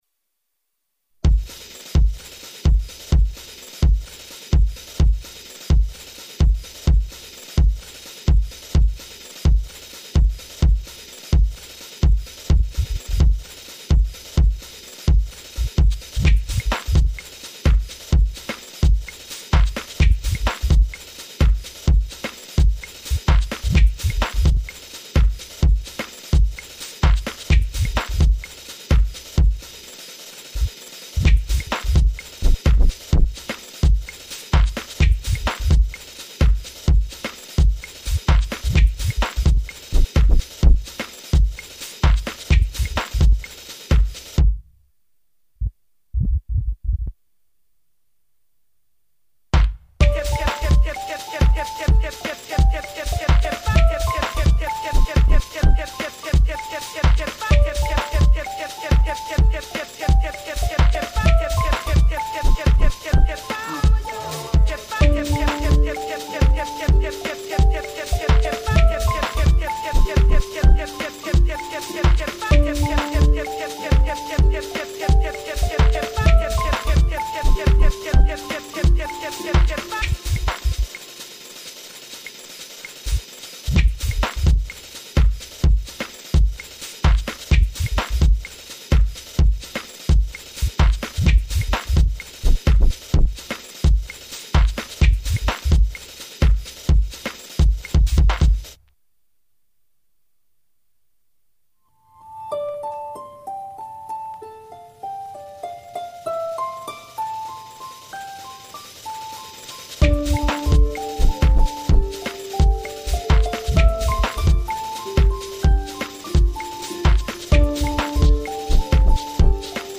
Really pleasant and easy sounds to listen to while working.